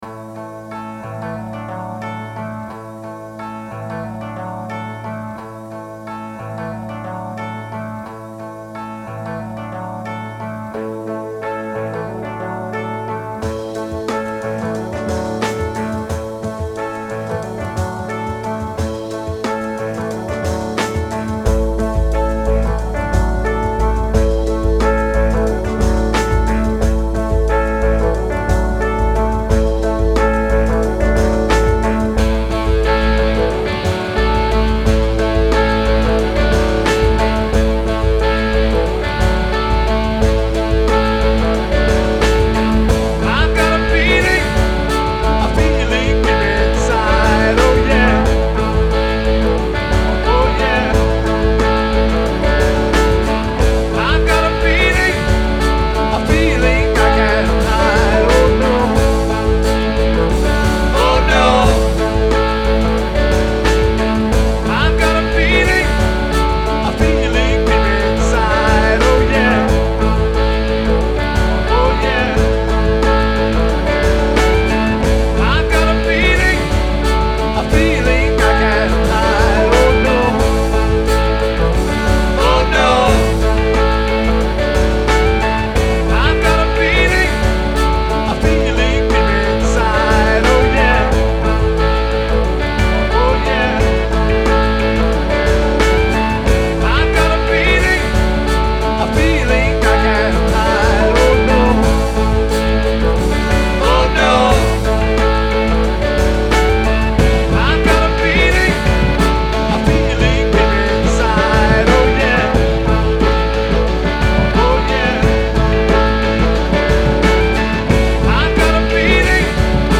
DJ mixes
remix 0 comments